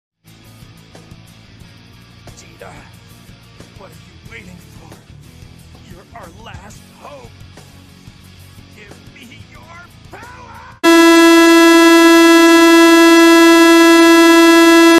Goku scream Wii crash meme sound effects free download